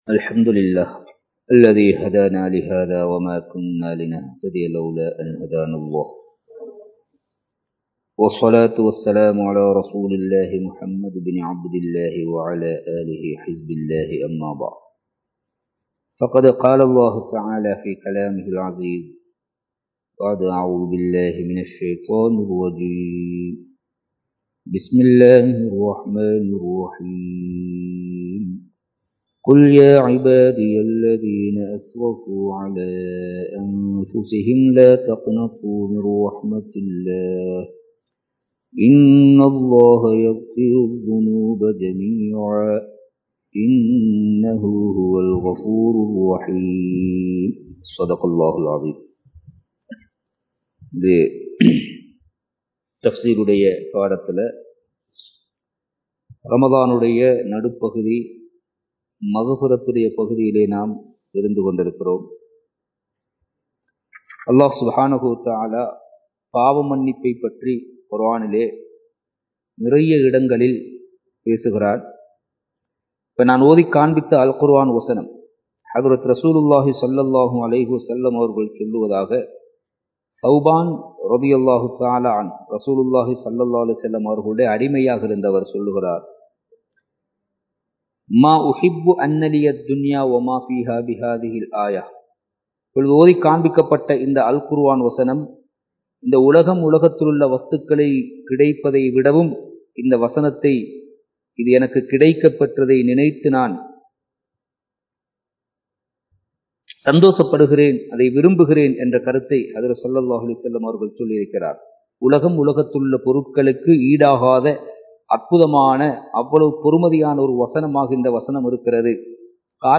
தௌபாவும் முடிவும் (Thafseer 18) | Audio Bayans | All Ceylon Muslim Youth Community | Addalaichenai
Kandy, Kattukela Jumua Masjith